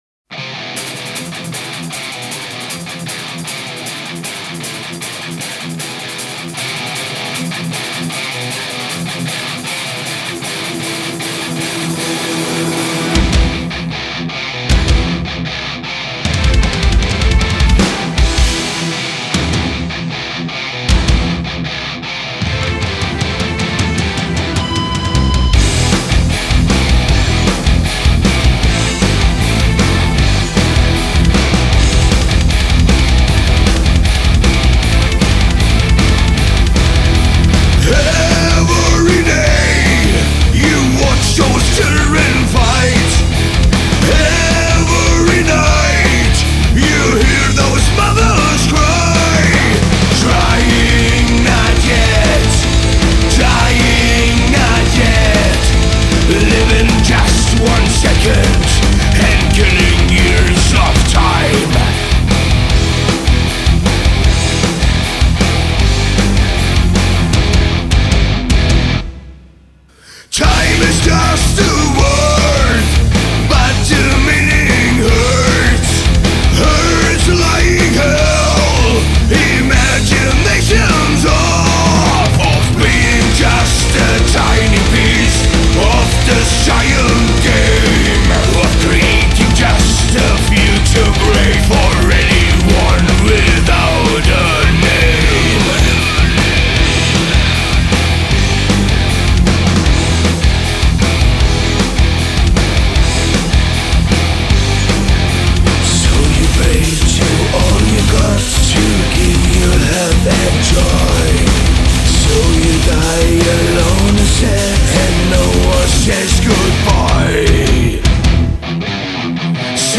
Female Vocals